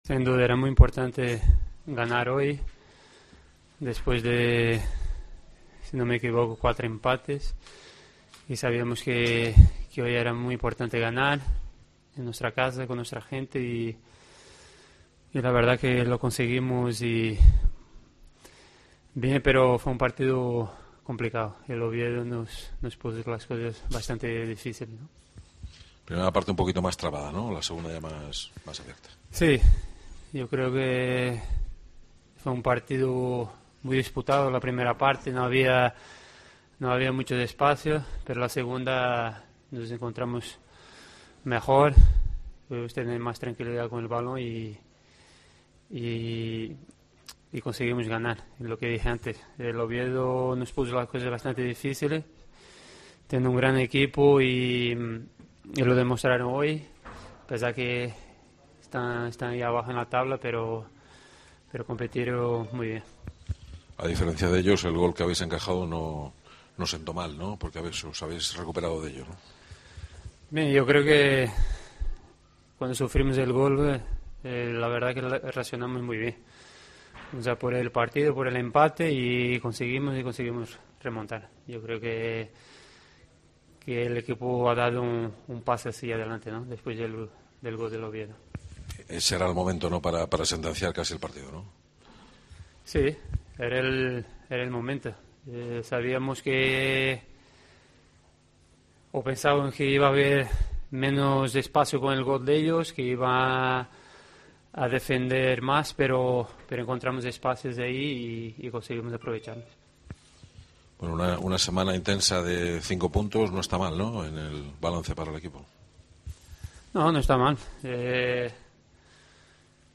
Escucha aquí las palabras de los dos jugadores de la Deportiva Ponferradina